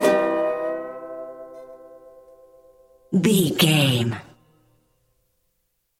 Ionian/Major
acoustic guitar
banjo
percussion